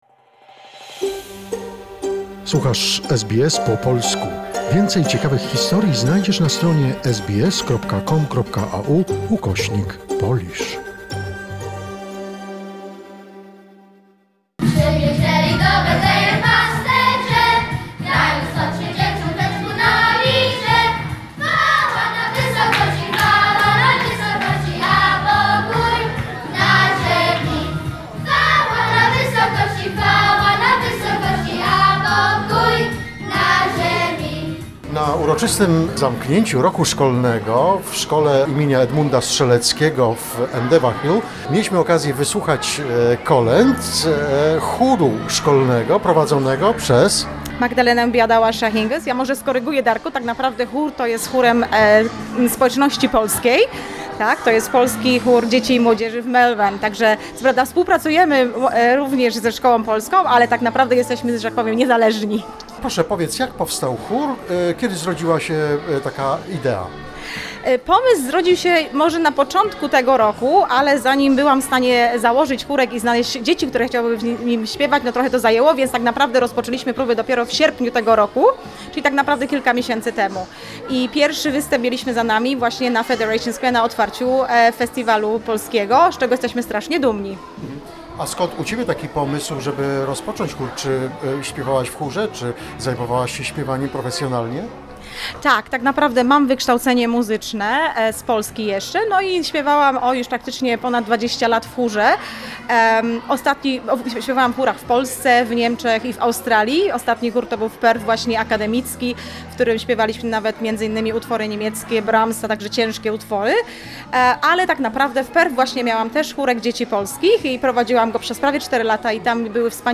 Children sing carols
we could sing a carols with the Children's Choir
Chór dzieci w szkole im.Edmunda Strzeleckiego w Endeavour Hills , Melbourne.